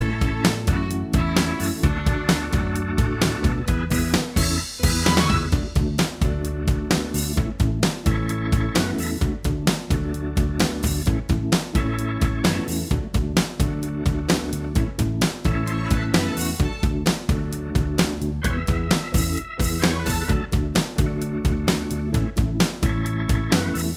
Minus All Guitars Easy Listening 3:17 Buy £1.50